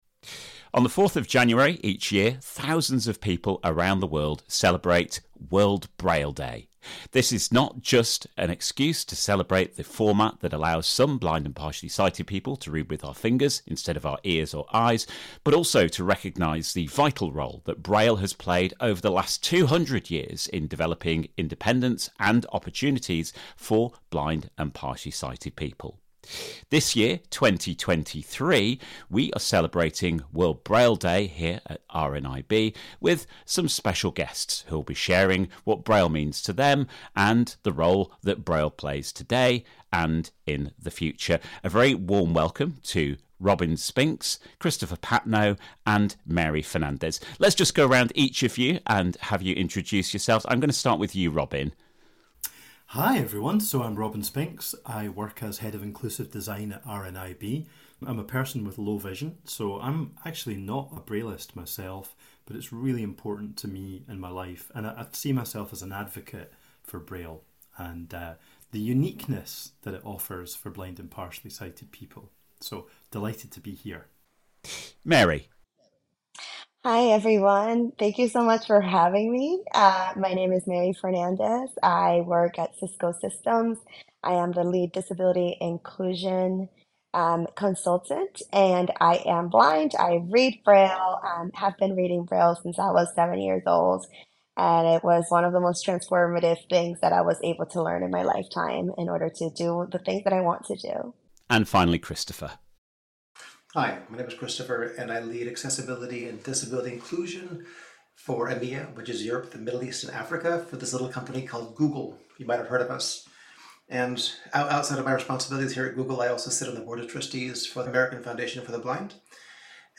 World Braille Day 2023 - Panel Discussion